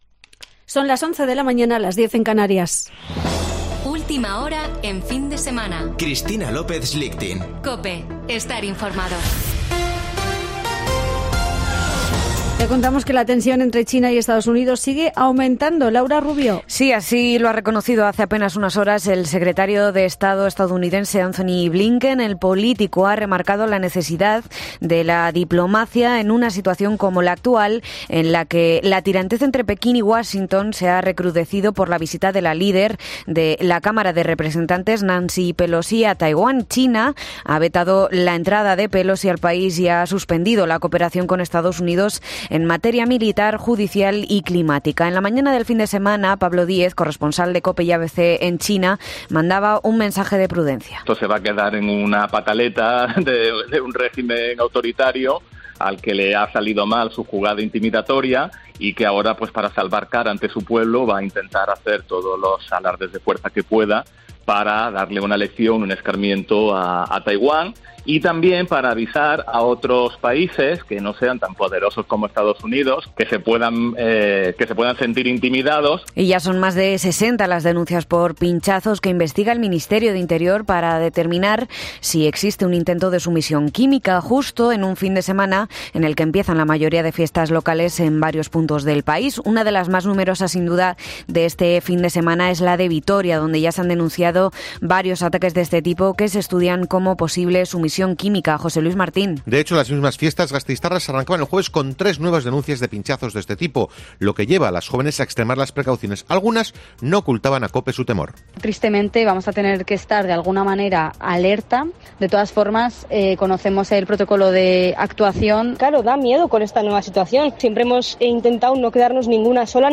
Boletín de noticias de COPE del 6 de agosto de 2022 a las 11.00 horas